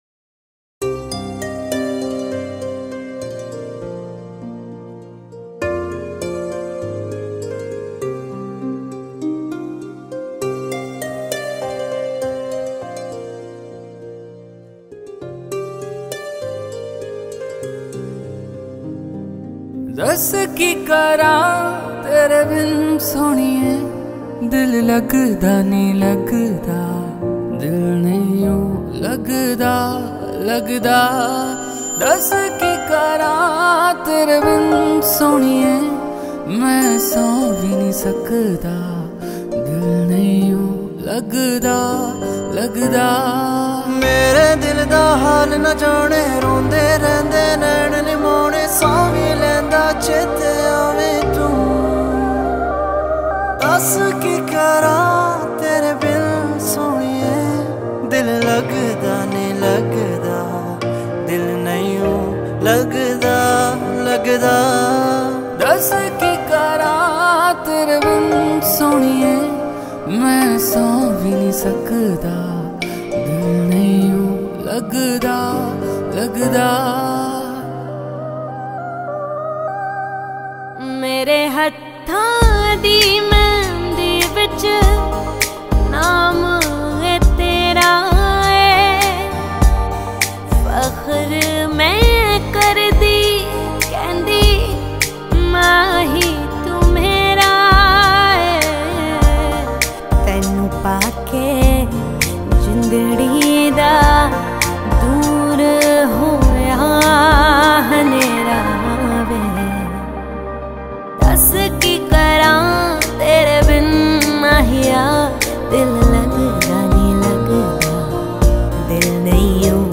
Shehnai